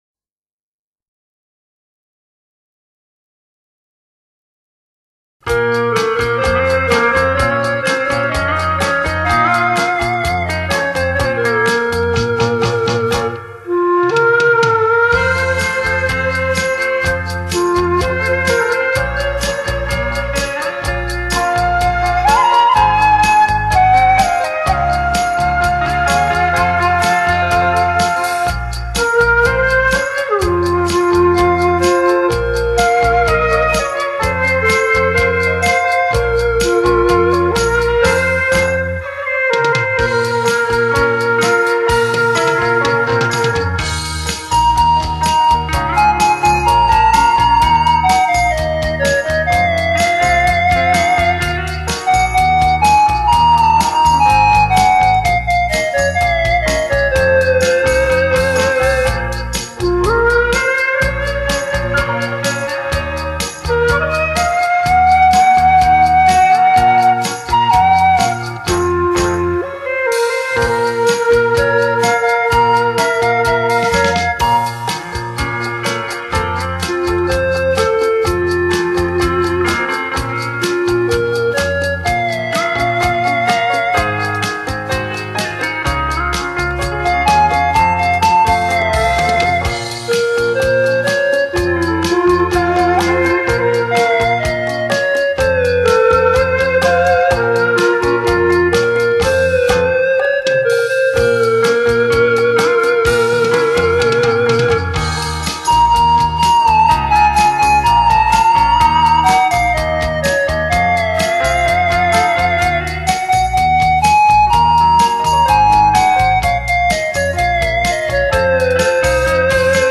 迂回婉转的音律，卓越深厚的演奏、憾人肺腑的乐魂、令你领略到无限的中国音乐魅力。
吹奏乐器，多用于独奏、合奏和歌唱的伴奏，常处于领奏地位，由于发音原理的不同，其种类和音色极为丰富多彩，个性极强。